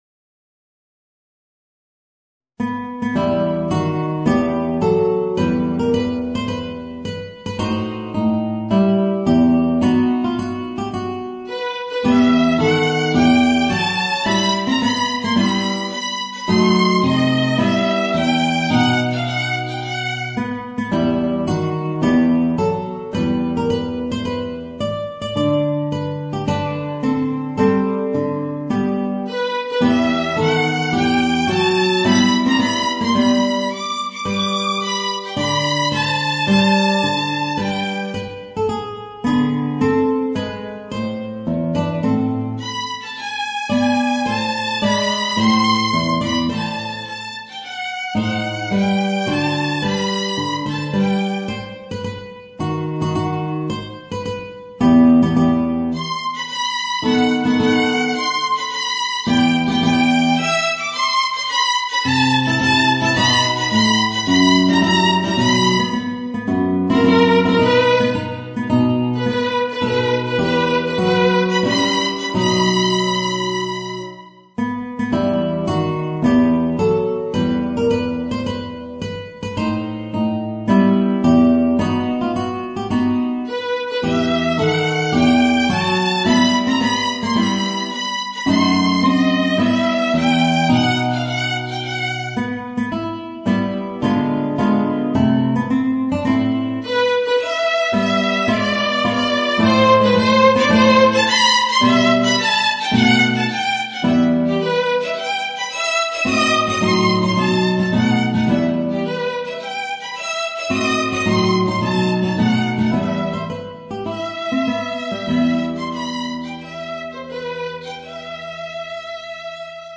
Voicing: Violin and Guitar